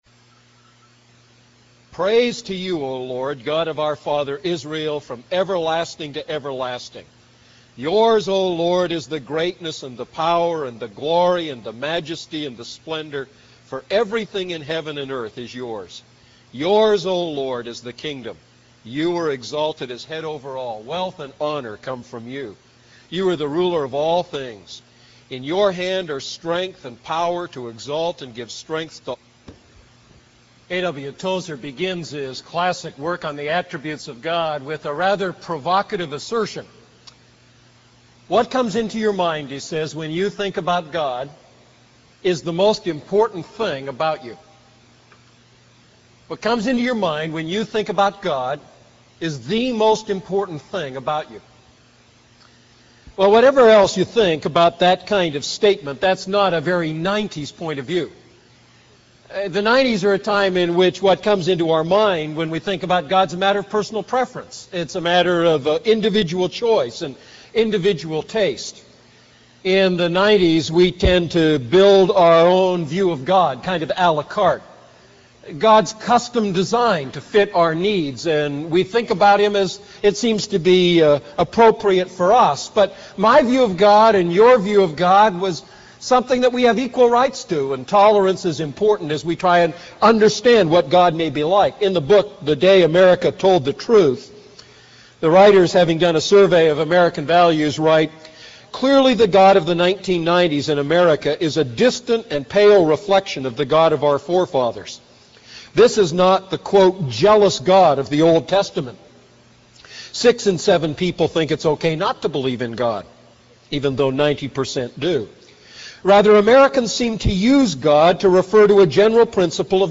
A message from the series "The Ten Commandments."